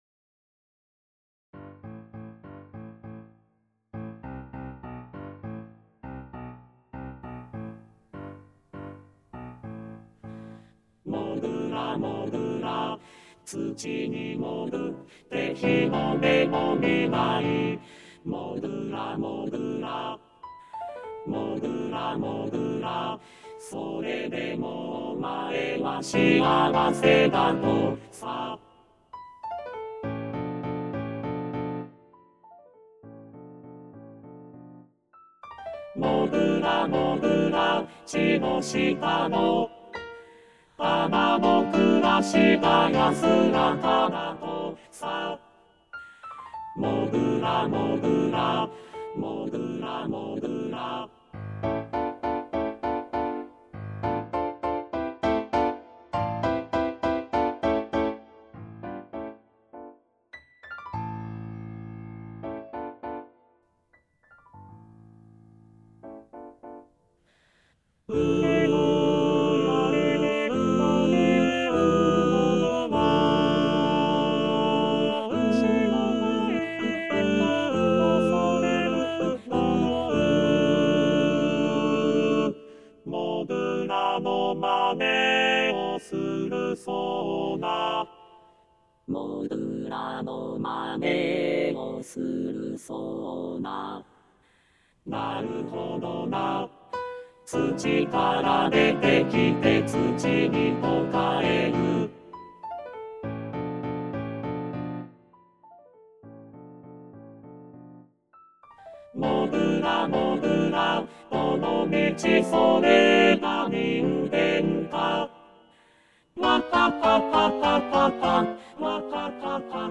★第１２回定期演奏会　演奏曲　パート別音取り用 　機械音声(ピアノ伴奏希望はｽｺｱｰﾌﾟﾚｱｰsdxで練習して下さい)
第四楽章 もぐらもち【全パート】(修正版).mp3